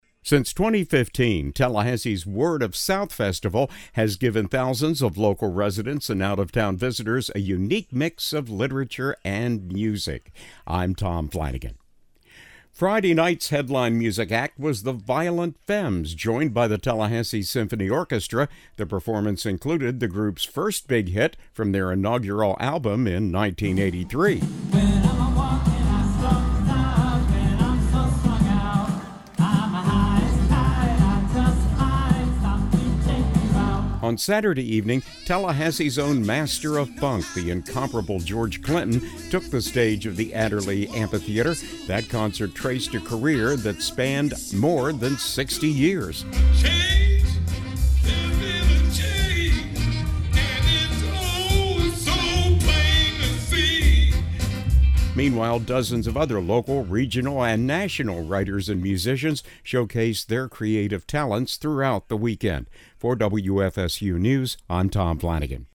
Femmes and Funk get the crowds dancing at this year’s Word of South Festival
Friday night’s headline music act was the Violent Femmes, joined by the Tallahassee Symphony Orchestra.
On Saturday evening, Tallahassee’s own Master of Funk, the incomparable George Clinton, took the stage of the Adderley Amphitheatre.